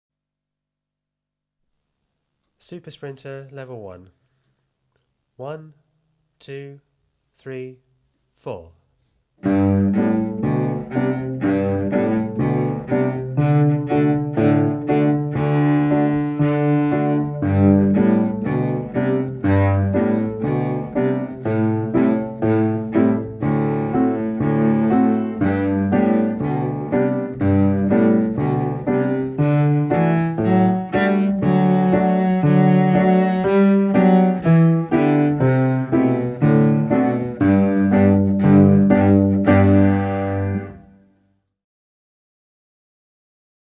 52 Super sprinter - Level 1 (60) (Cello)